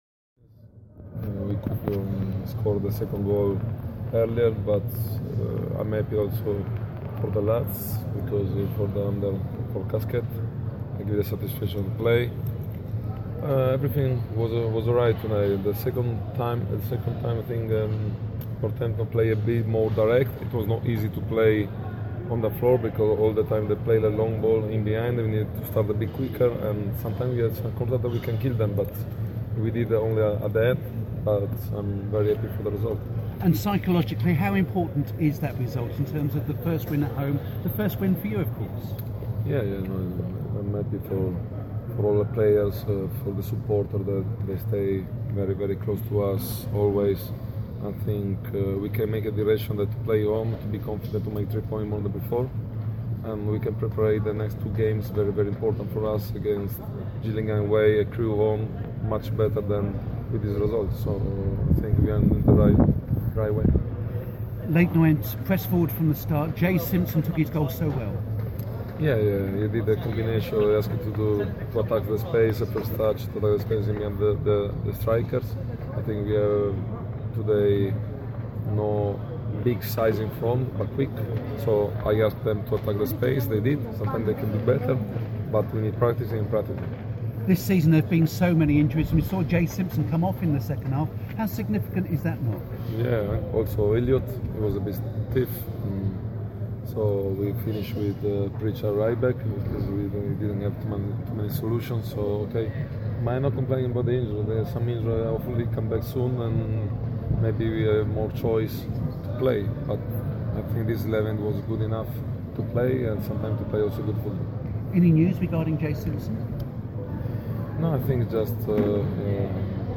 Mauro Milanese, speaking after Orient's 2-0 JPT victory against Northampton